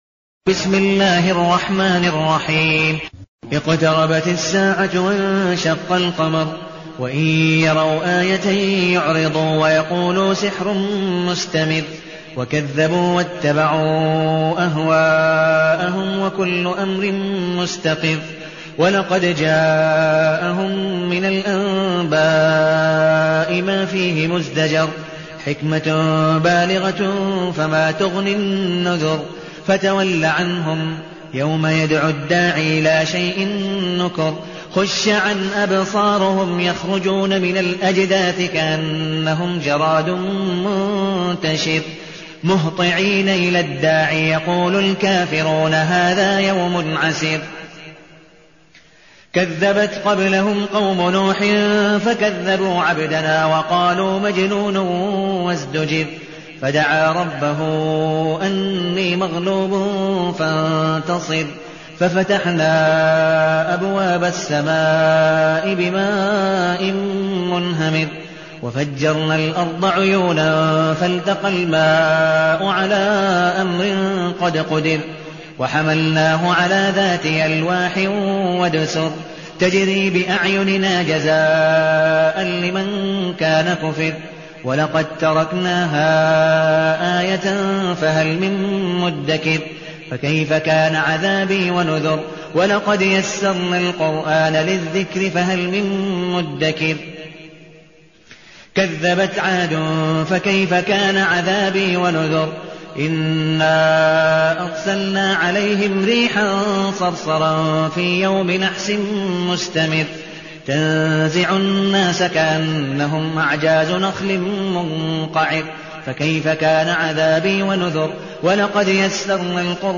المكان: المسجد النبوي الشيخ: عبدالودود بن مقبول حنيف عبدالودود بن مقبول حنيف القمر